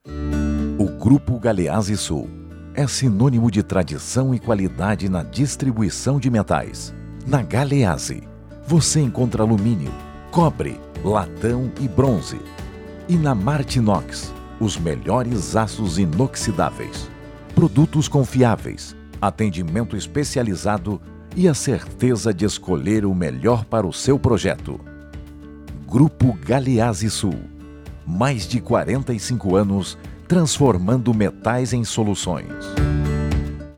Institucional: